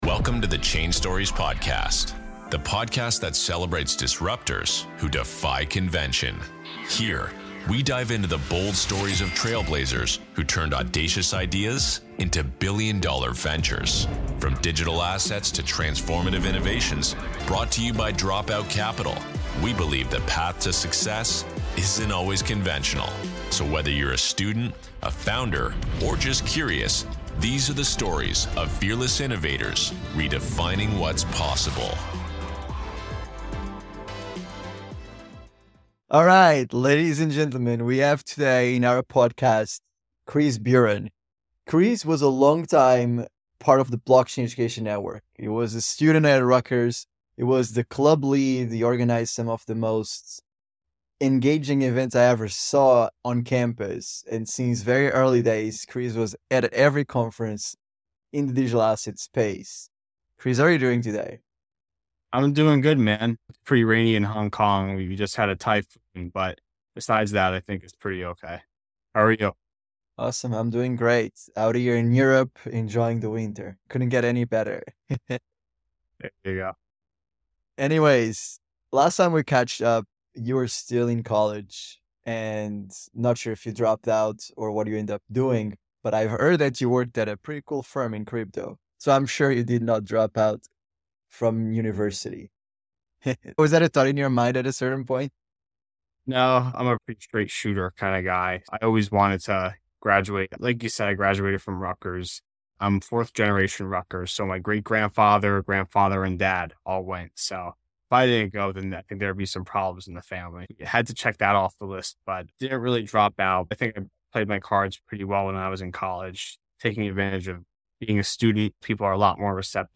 The Role of Perpetual Swaps - Interview with Quantitative Trading Analyst